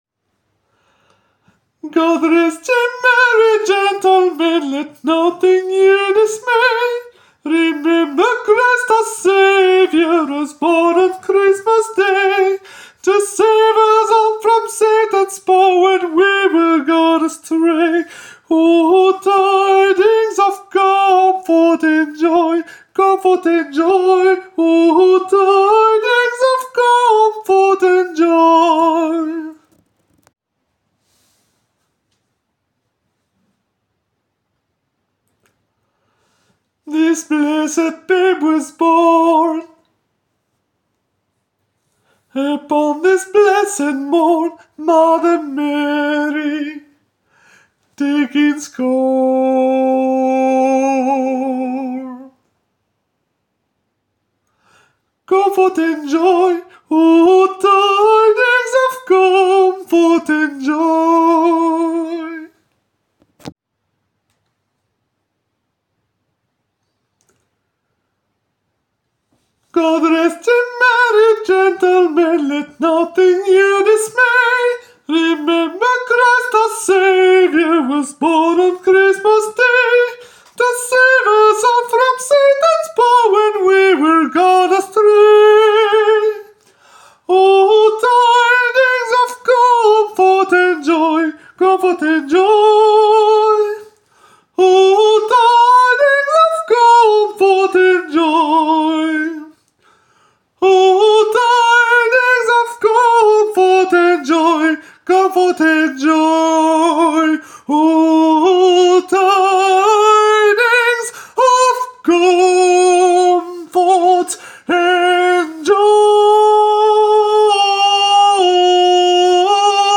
alto
GOD-REST-YE-MERRY-GENTLEMEN-alto.aac